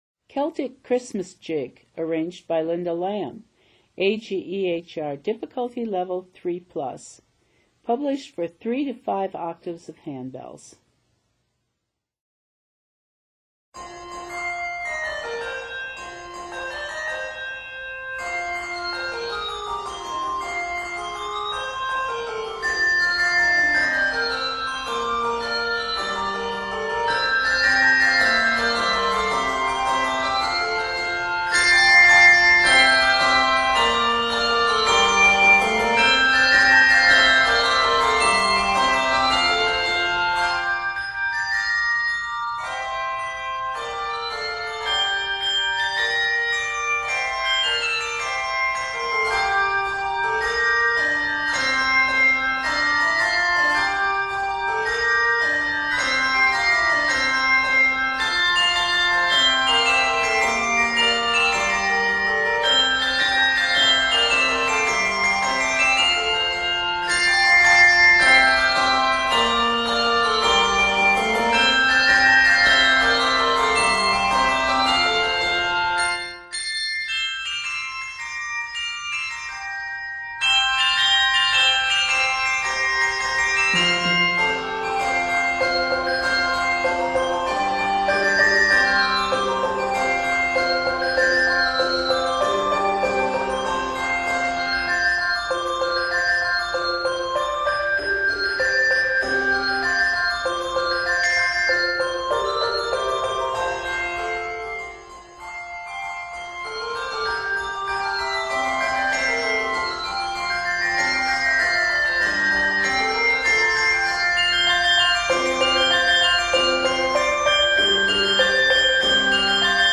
Combining two jigs, one Scottish and one Irish,